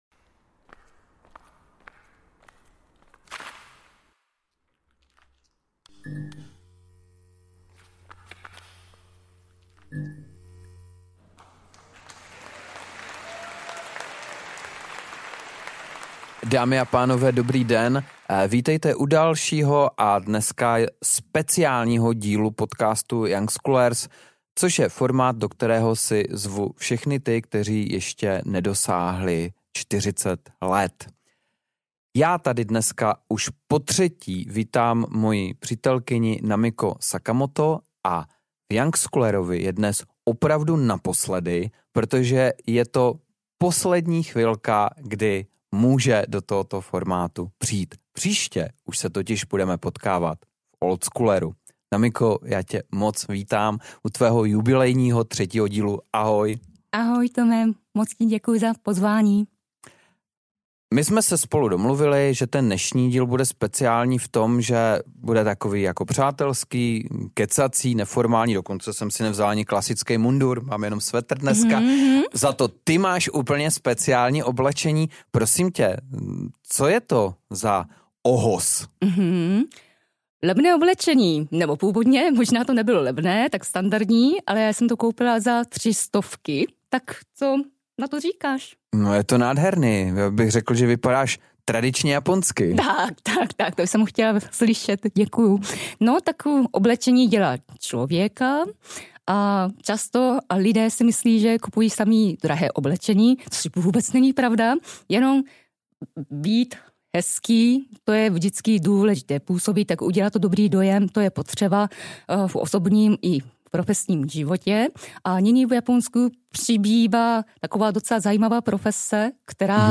Přišla do studia v pátek 23.9.2022. Vítejte u zajímavého rozhovoru.